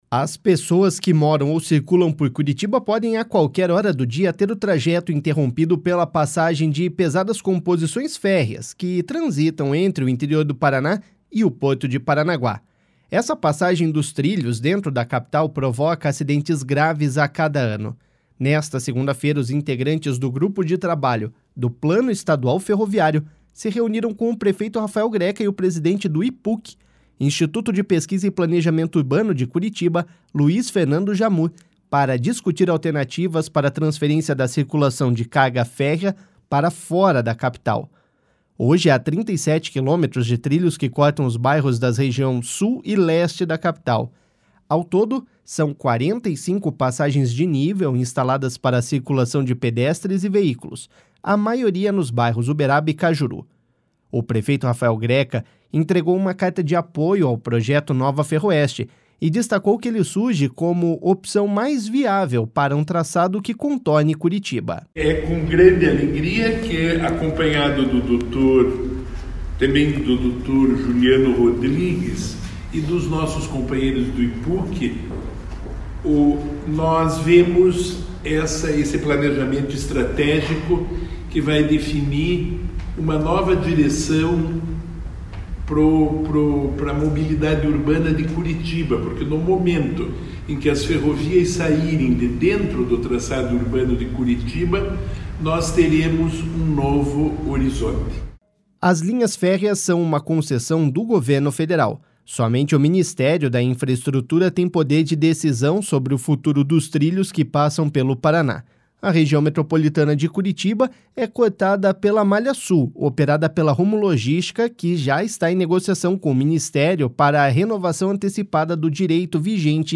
O prefeito Rafael Greca entregou uma carta de apoio ao projeto Nova Ferroeste, e destacou que ele surge como a opção mais viável para um traçado que contorne Curitiba.// SONORA RAFAEL GRECA.//